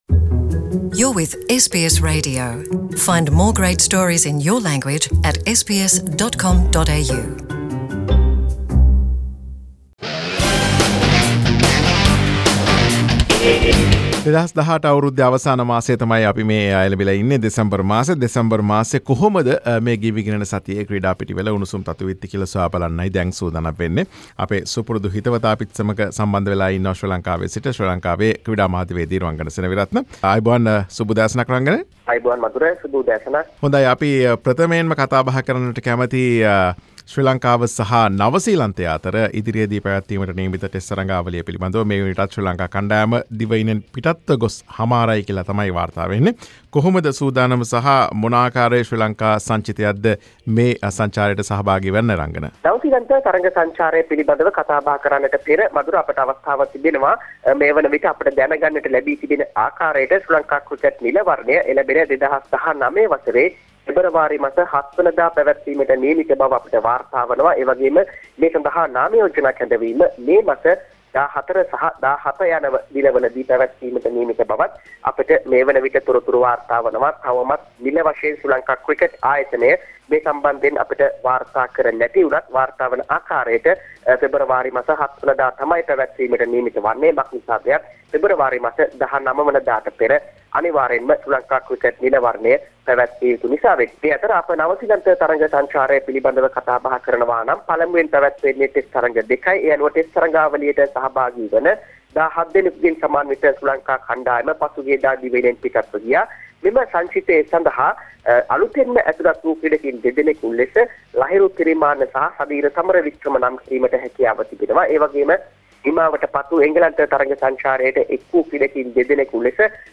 sports wrap